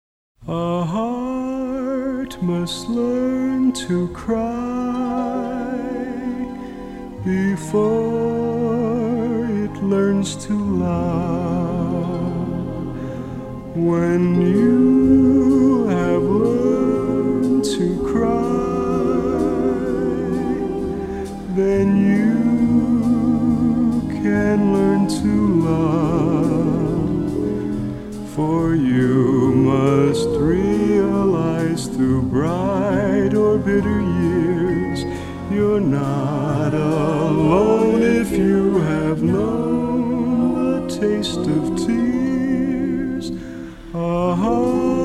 remastered in stereo from the original three-track masters